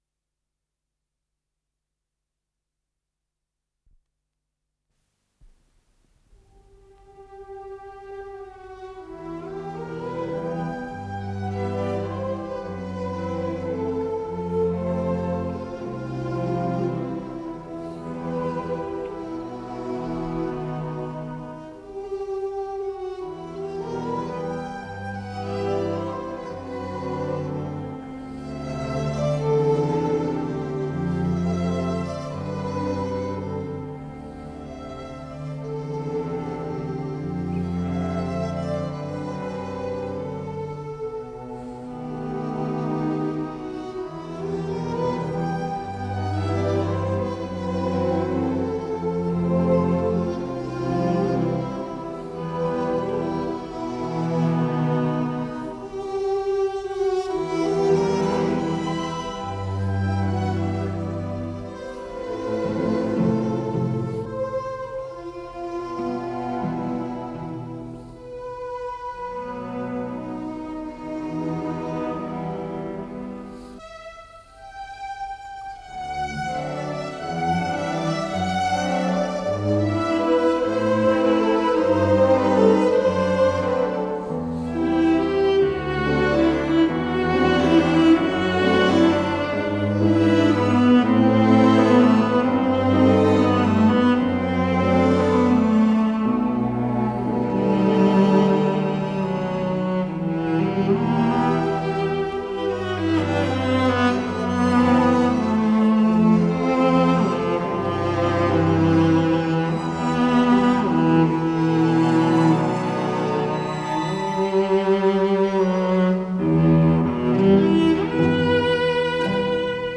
с профессиональной магнитной ленты
переложение в ми миноре
ВариантДубль стерео